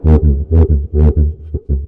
描述：普蒂普是一种打击乐器，用于那不勒斯的民间音乐，一般来说，用于意大利南部大部分地区的民间音乐。
普蒂普这个名字是乐器演奏时发出的"打嗝"的拟声词。
然后，空气从将膜固定在乐器的粘土或金属主体上的不完全密封处喷出，声音很大。
标签： 摩擦 caccavella 粘土 民族 民俗 那不勒斯 putip 敲击
声道立体声